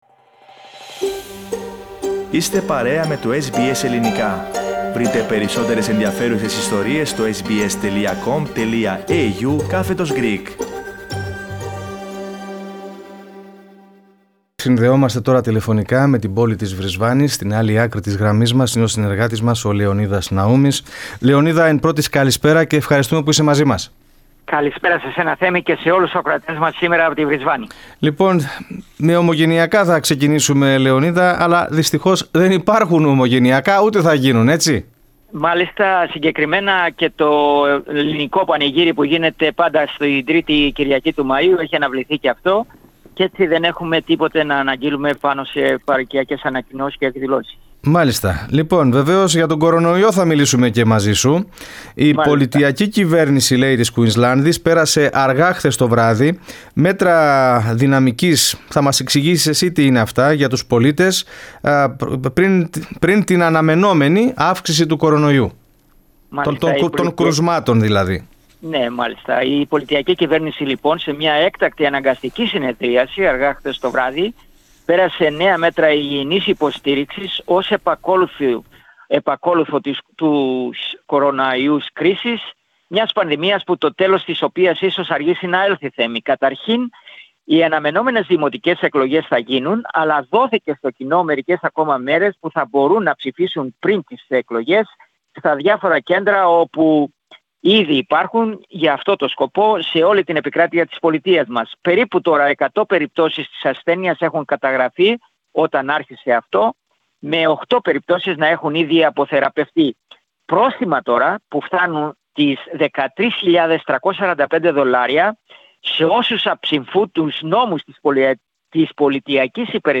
Πατήστε play στην κεντρική φωτογραφία για να ακούσετε ολόκληρο το podcast με την ανταπόκριση.